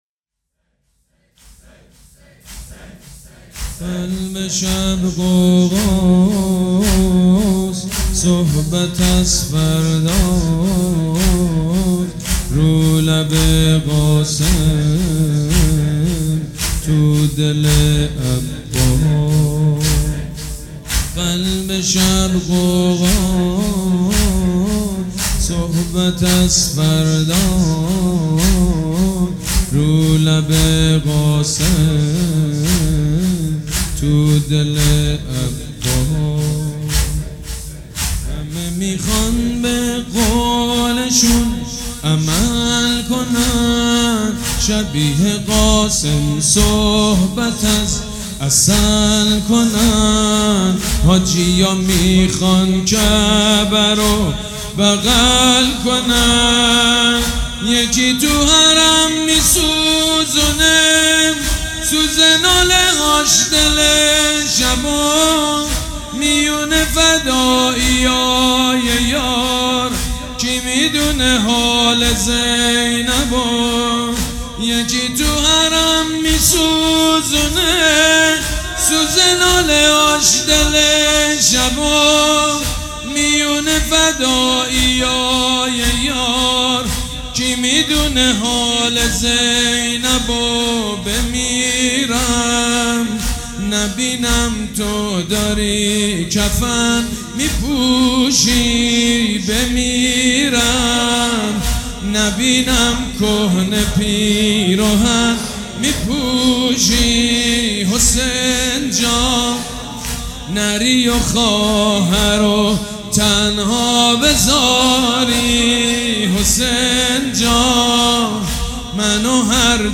مداحی زمینه سید مجید بنی فاطمه در شب عاشورا - تسنیم
به گزارش باشگاه خبرنگاران پویا، مراسم عزادرای شب عاشورا با مداحی حاج سید مجید بنی فاطمه در هیئت ریحانه الحسین (ع) با حضور پرشور مردم برگزار شد. در ادامه صوت زمینه این مراسم را می‌شنوید.